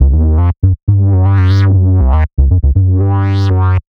Bassline S
TechBassS120C-04.wav